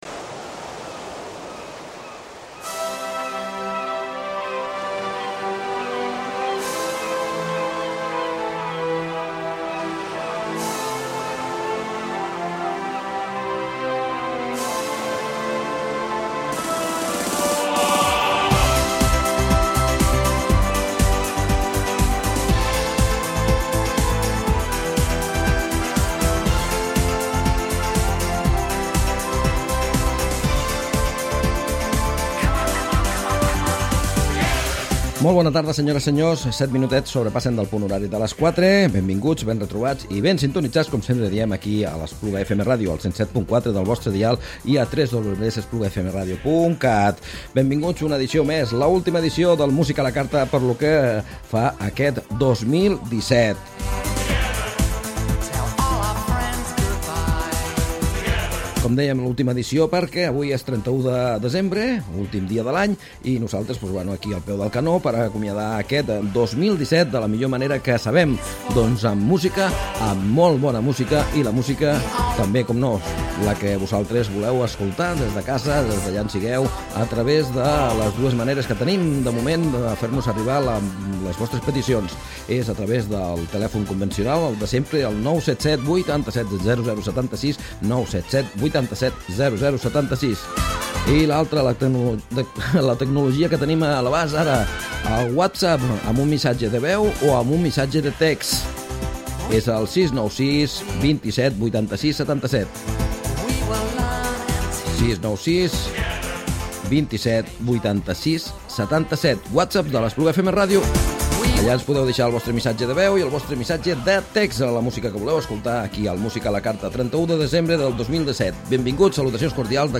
Escoltem les cançons escollides pels oients i t’expliquem alguns dels detalls de les produccions musicals que han marcat a més d’una generació.